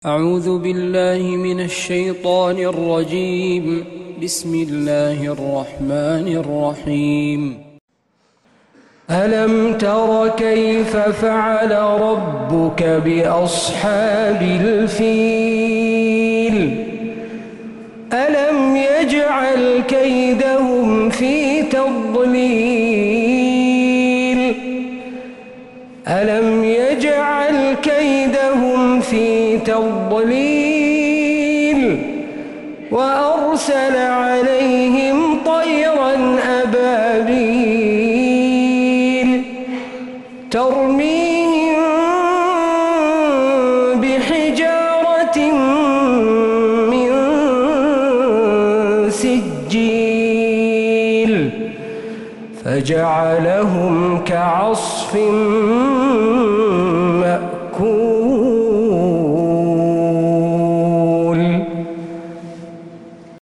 من مغربيات الحرم النبوي